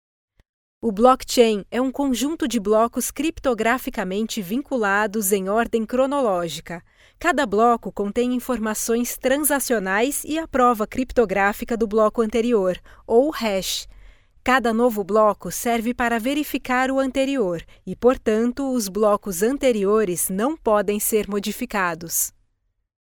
locutora brasil, brazilian voice over